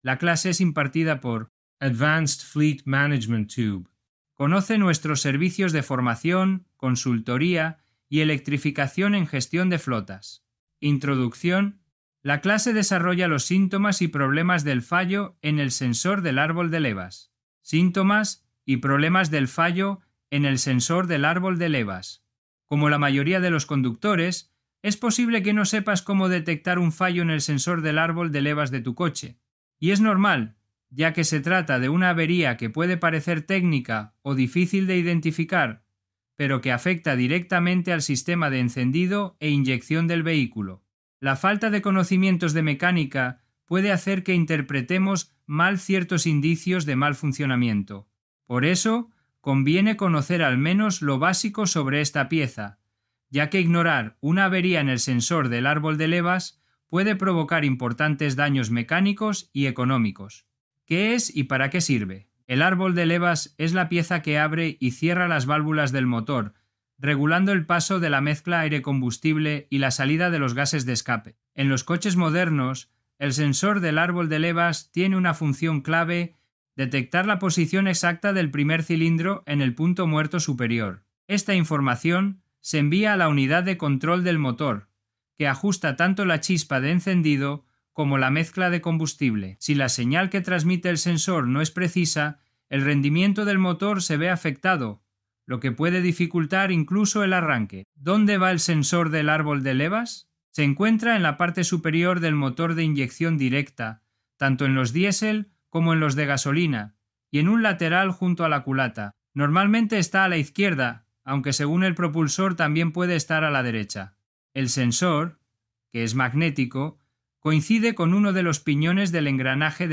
La clase desarrolla los síntomas y problemas del fallo en el sensor del árbol de levas.